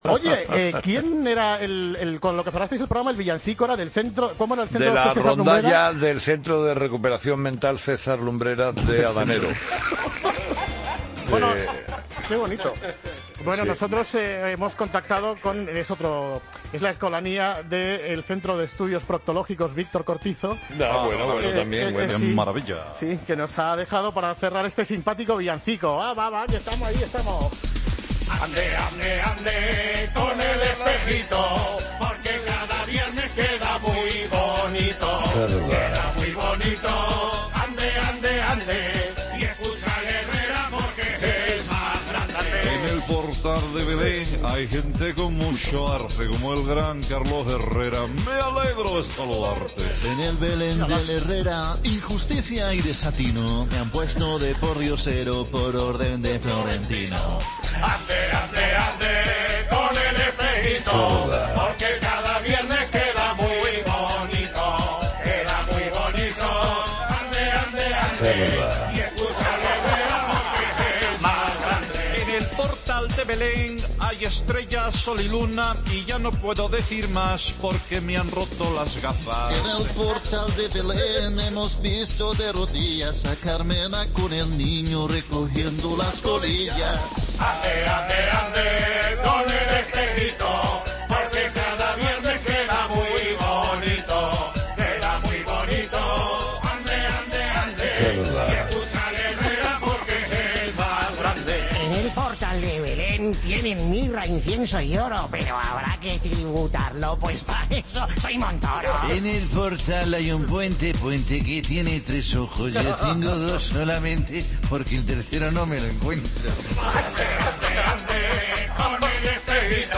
'Ande, ande', el villancico del Grupo Risa en 'Herrera en COPE'